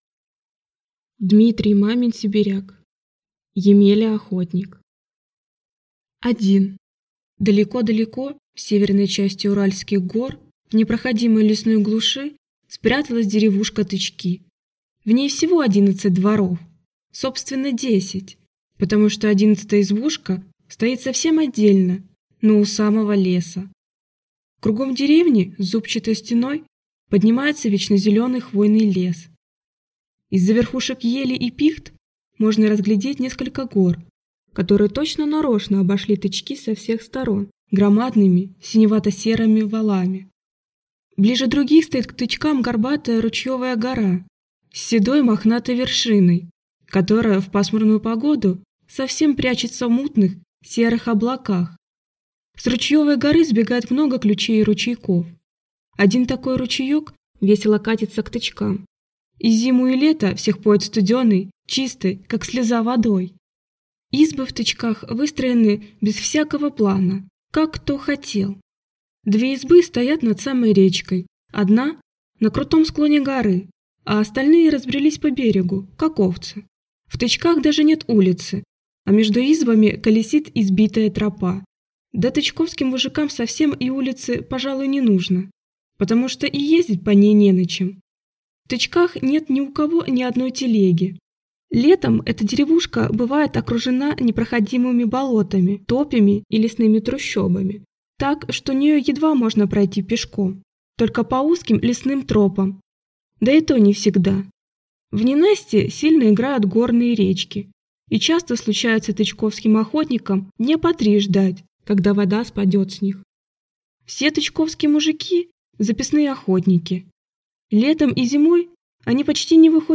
Аудиокнига Емеля-охотник | Библиотека аудиокниг